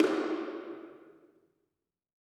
JJPercussion (266).wav